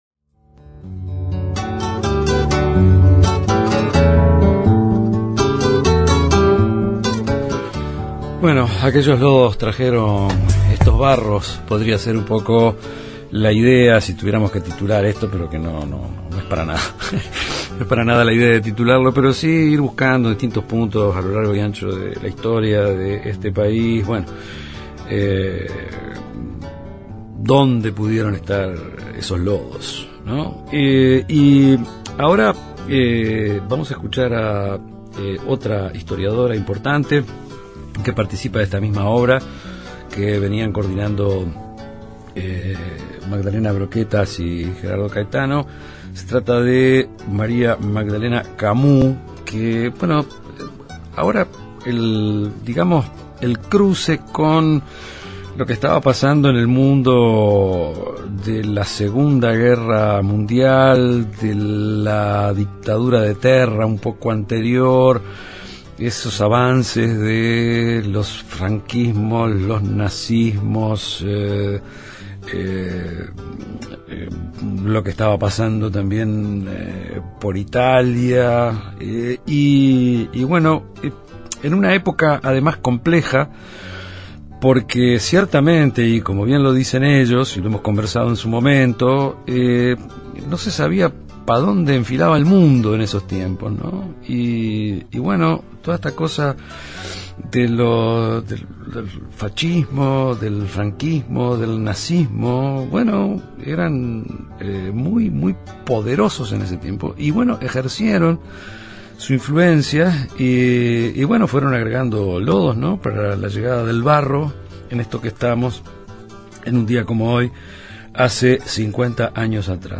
Programa especial con entrevistas a Gerardo Caetano